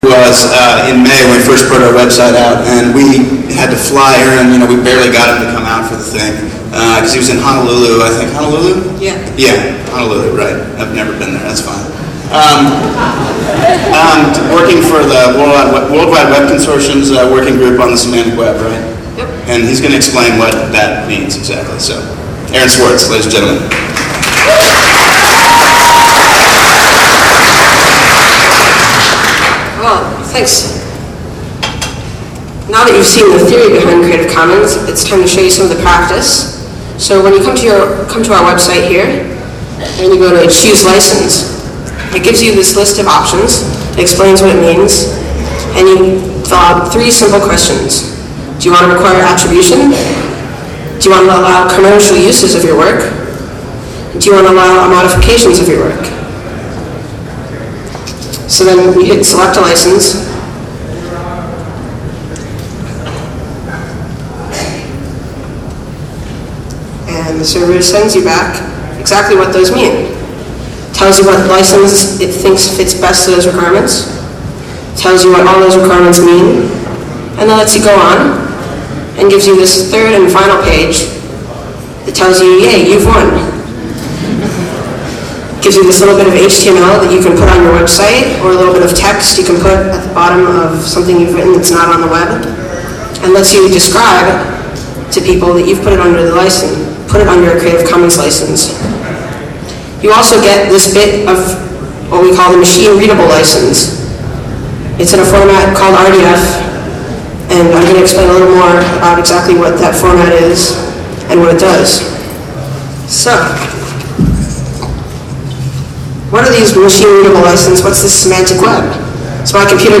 6. Lessig even brought Swartz on stage to make a presentation during the launch of Creative Commons in December 2002.